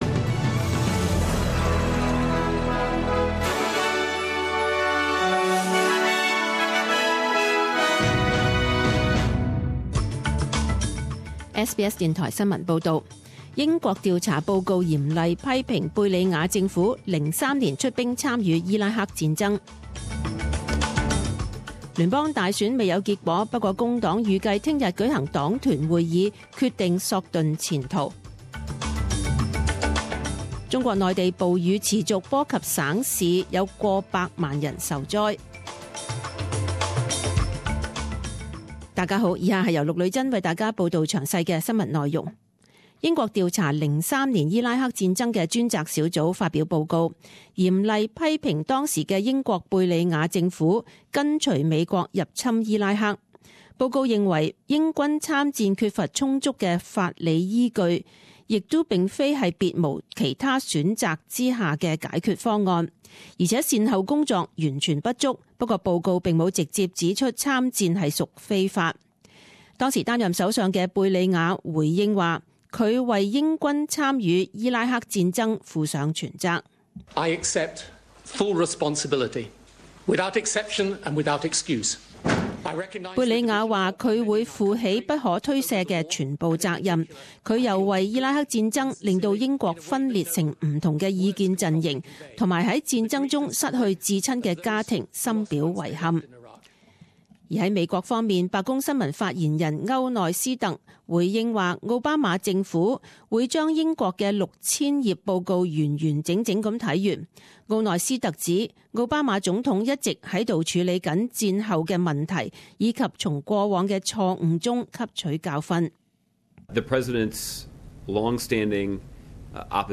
十点钟新闻报导（七月七日）
请收听本台为大家准备的详尽早晨新闻。